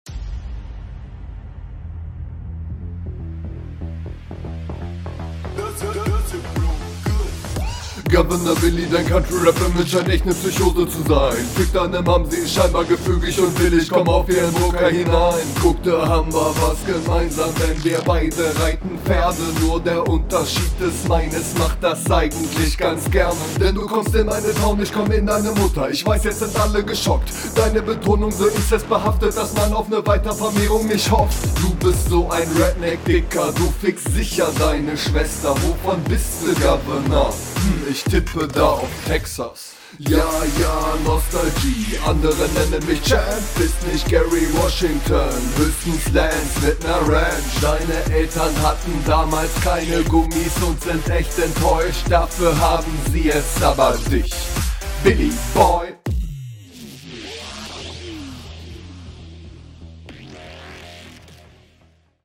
Die ersten lines sind schwer zu verstehen aber das pendelt sich dann ganz gut ein …